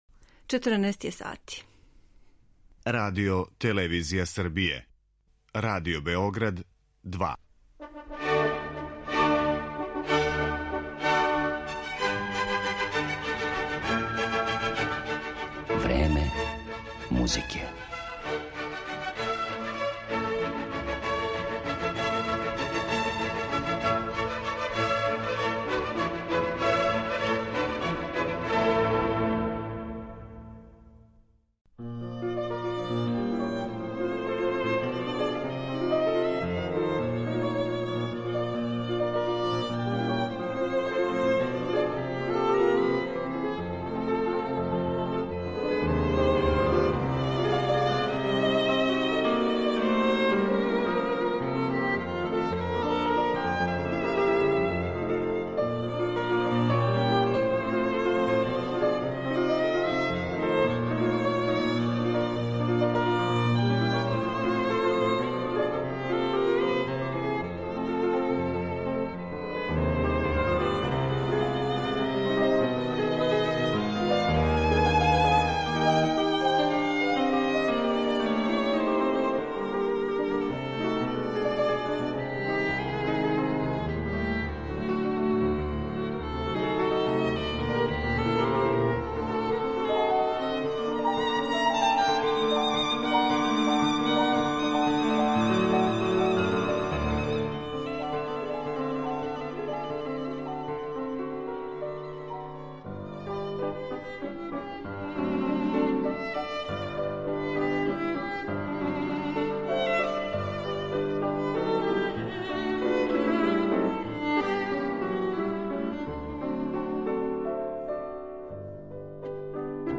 Тим поводом, овај наш прослављени виолиниста гост је данашње емисије.